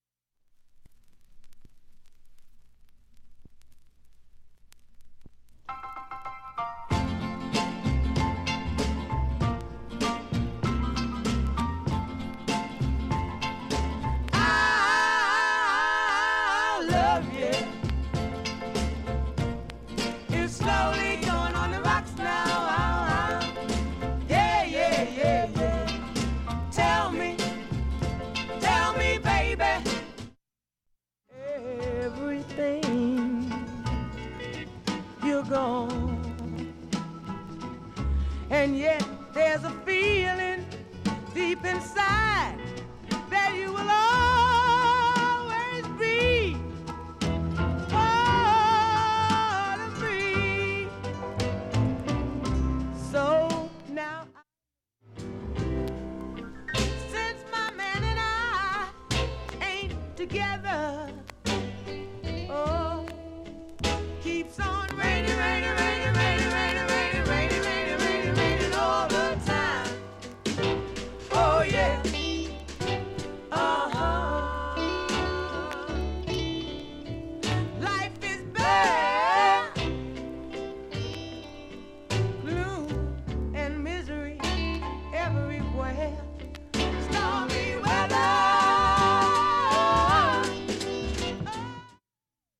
SOUL、FUNK、JAZZのオリジナルアナログ盤専門店
A-6途中バブルでわずかなポツ数回出ます。 B-4にわずかなプツ７回と３回出ます。
音質目安にどうぞ ほかきれいで、音質は良好です全曲試聴済み。
MONO